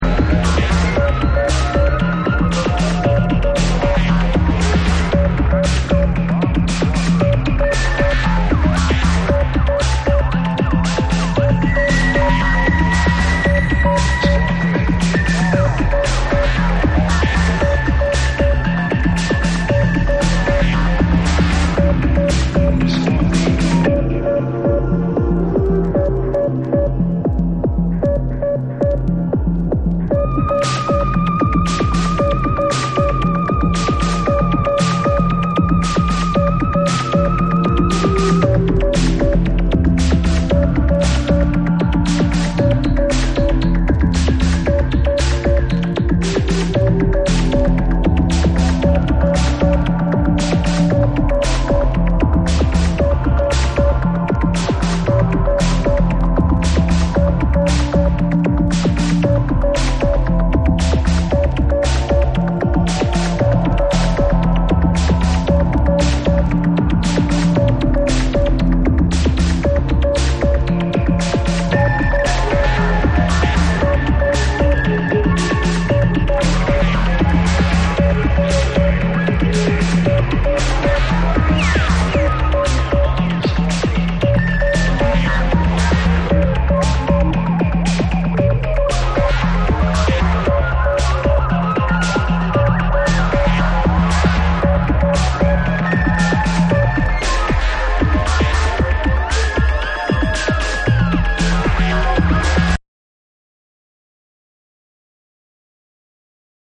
ディープかつスピリチュアルな音空間の中に、アブストラクト・ジャズが溶け込むサイケデリック・ブレイクビーツ。
BREAKBEATS